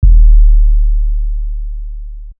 Mob Ties 808.wav